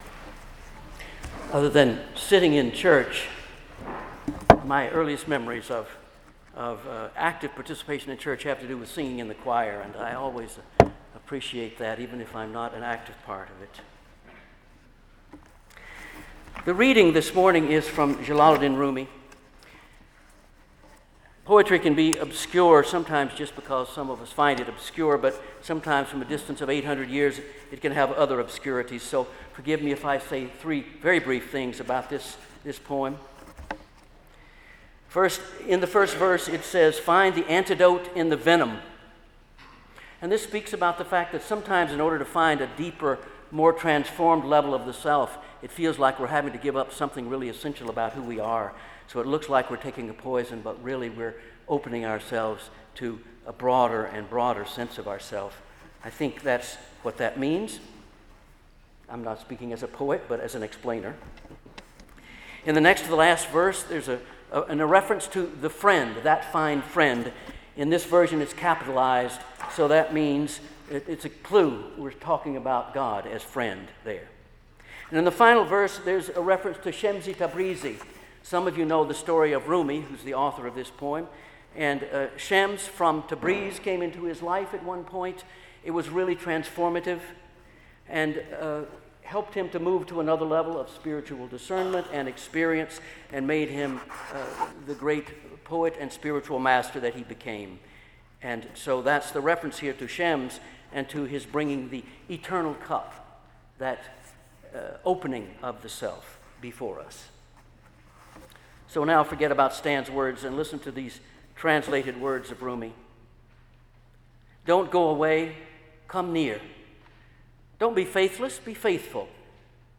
Listen to the Reading & Sermon
October-21-sermon.mp3